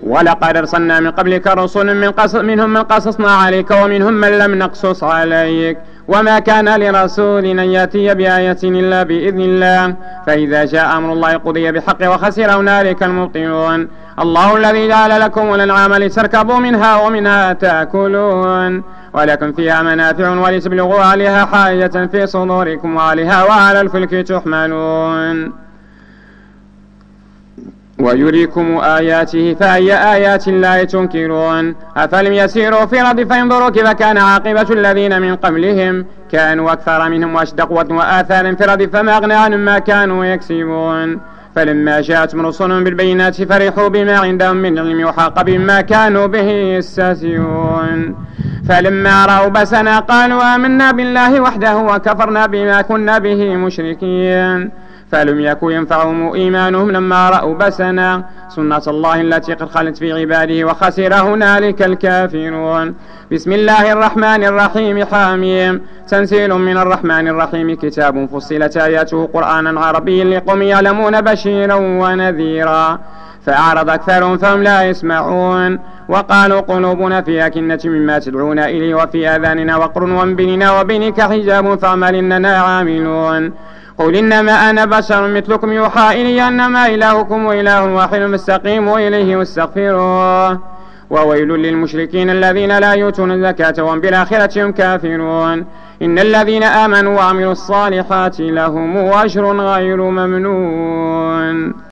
صلاة التراويح رمضان 1431/2010 بمسجد ابي بكر الصديق ف الزوى